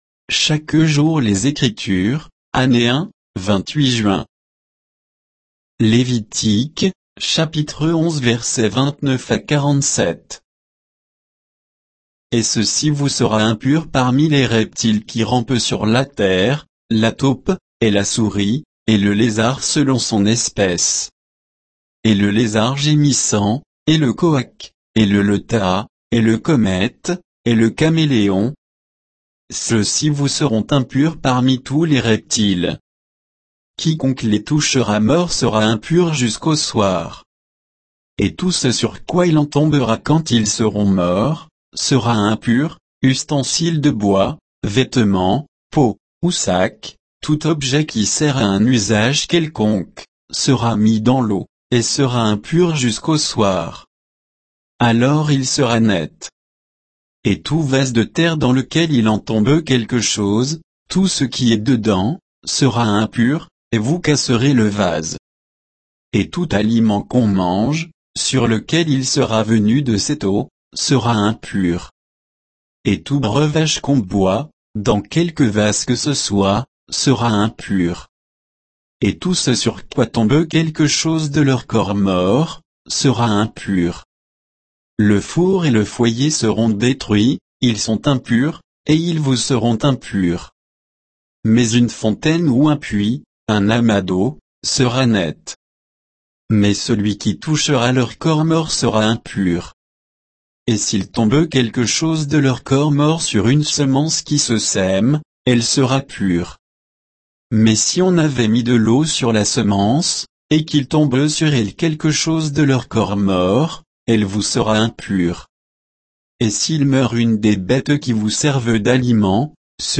Méditation quoditienne de Chaque jour les Écritures sur Lévitique 11